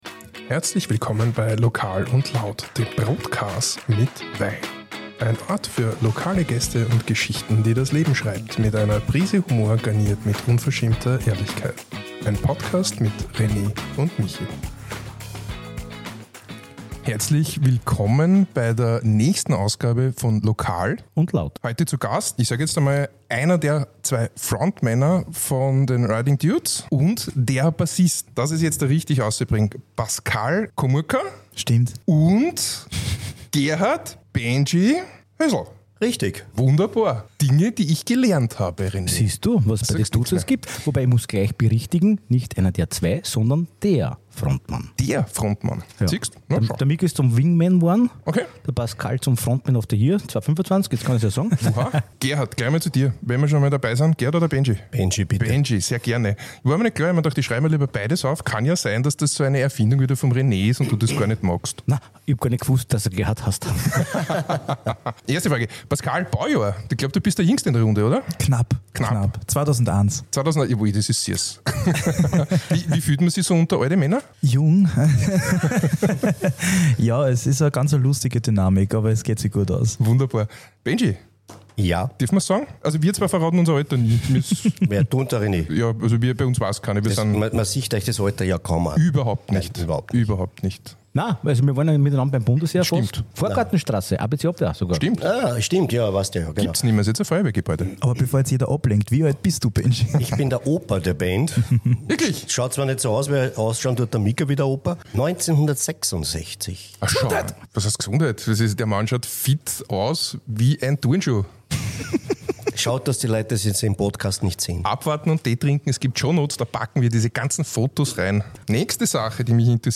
Ein Ort für Lokale Gäste und Geschichten die das Leben schreibt, mit einer Prise Humor garniert mit unverschämter Ehrlichkeit.